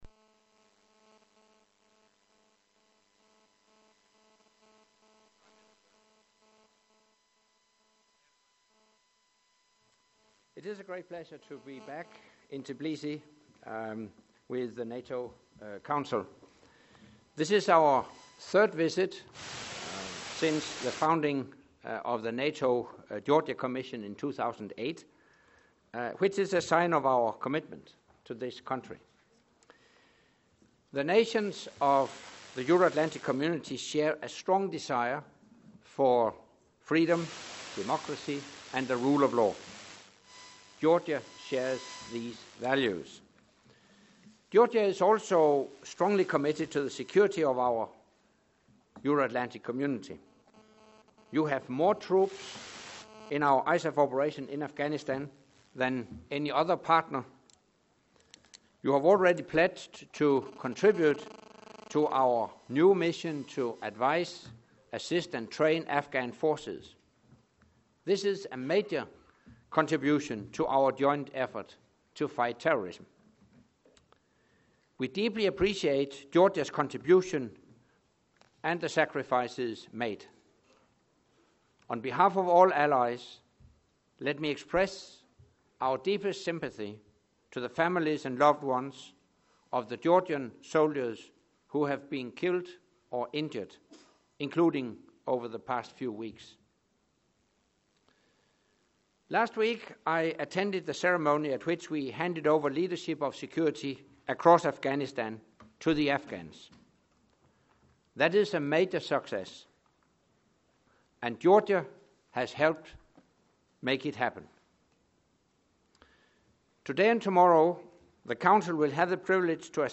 Opening remarks by NATO Secretary General Anders Fogh Rasmussen at the NATO-Georgia Commission meeting in Tbilisi, Georgia
Video NATO Secretary General with Prime Minister of Georgia - NATO-Georgia Commission, 26 June 2013 26 Jun. 2013 Audio Opening remarks by the NATO Secretary General and the Prime Minister of Georgia at the NATO-Georgia Commission meeting in Tbilisi, Georgia 26 Jun. 2013 | download mp3